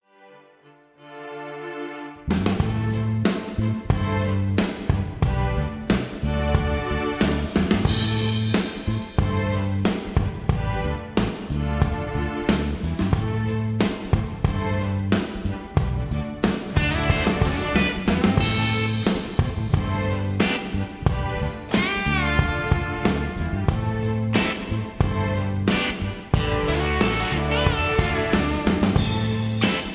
Tags: karaoke , backingtracks , soundtracks , rock